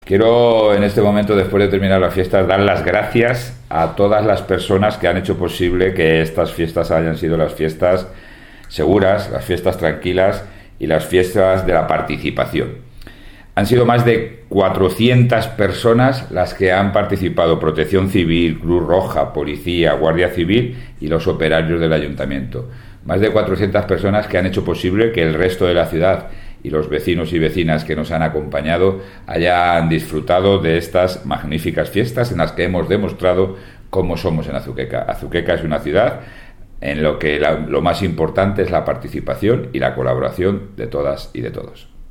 Declaraciones del alcalde José Luis Blanco 1